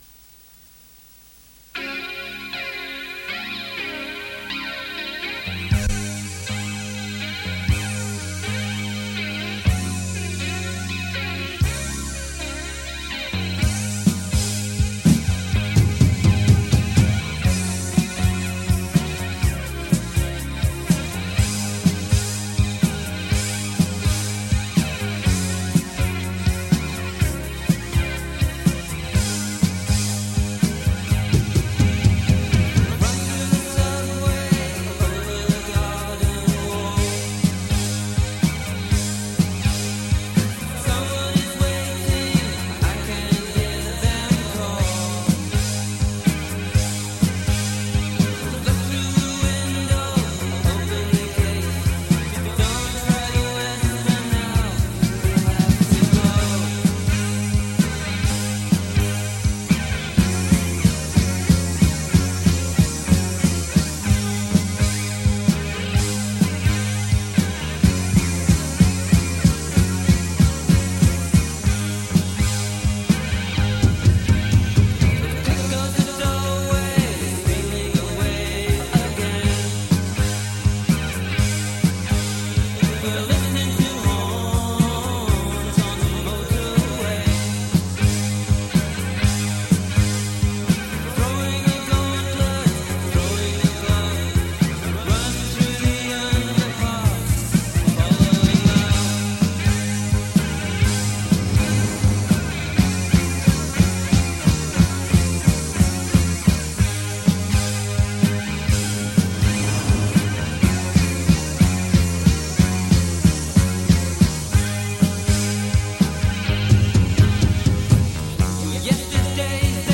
lead vocals, guitar and keyboards
drums